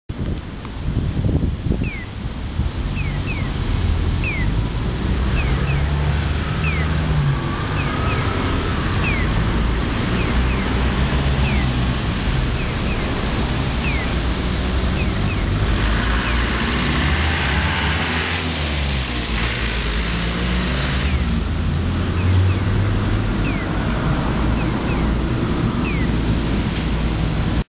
ピヨです。こちらは特に変わりありません。